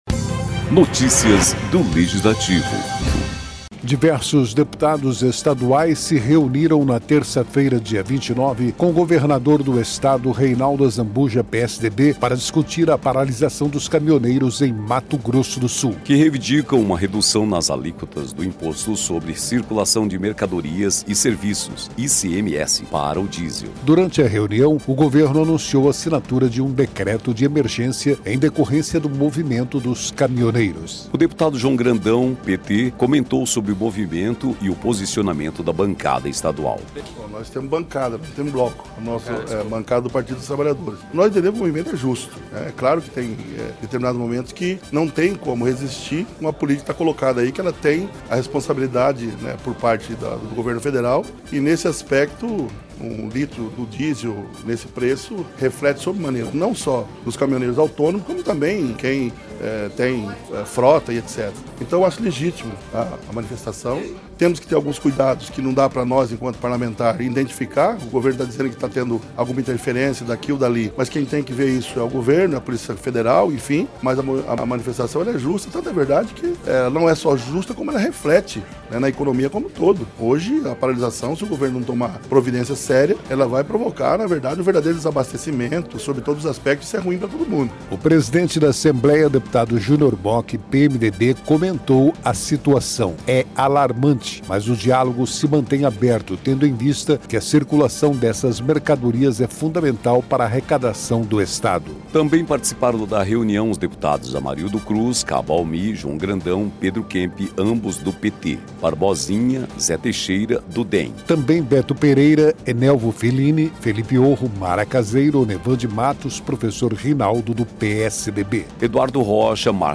O presidente da Assembleia, deputado Junior Mochi (PMDB), comentou a situação.